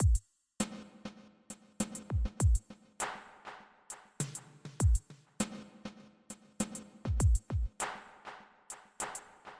Ритмический рисунок рабочего барабана я решил сделать минимальным. Просто расставил удары по чётным долям каждого такта.
Вторая хитрость заключается в том, что при обработке, кроме ревербератора, я применил ко всем трём сэмплам эффект задержки (delay).
Сведя вместе рабочий барабан с бас-бочкой и «хэтами» получим следующий